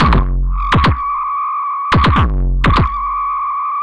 tx_perc_125_thumpwhine.wav